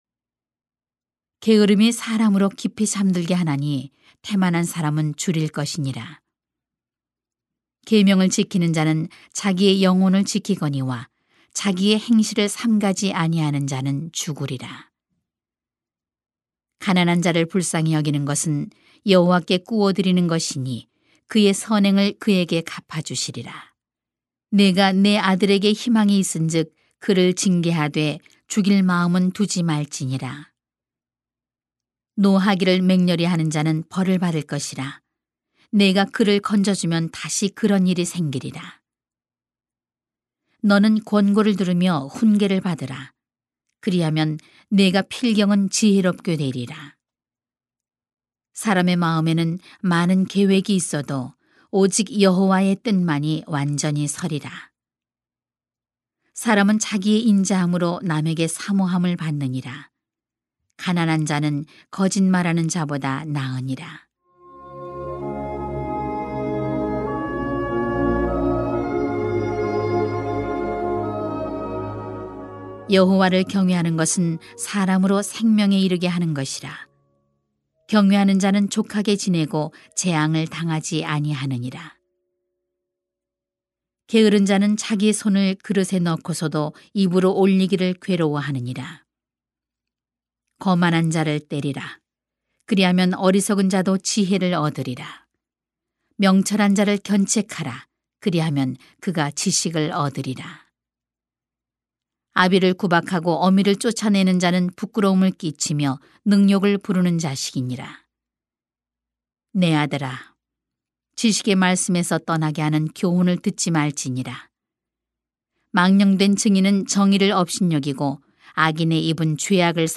2021.6.6 바른 교훈을 듣는 분별력이 필요합니다 > 주일 예배 | 전주제자교회